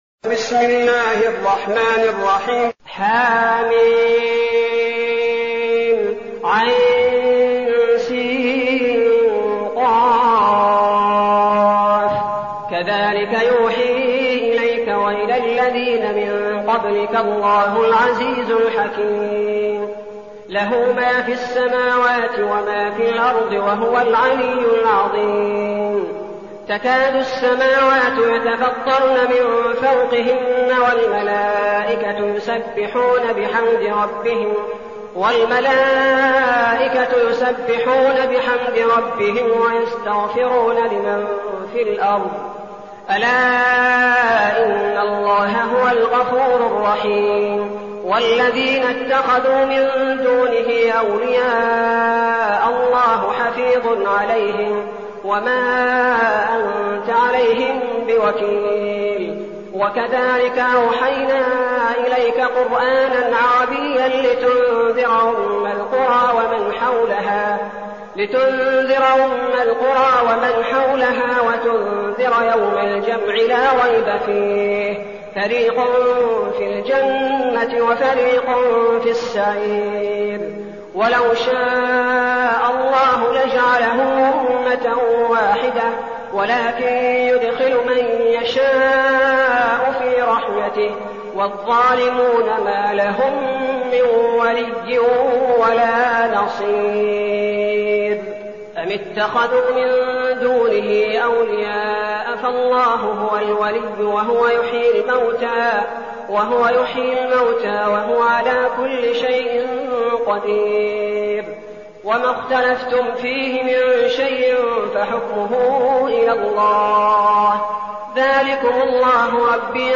المكان: المسجد النبوي الشيخ: فضيلة الشيخ عبدالباري الثبيتي فضيلة الشيخ عبدالباري الثبيتي الشورى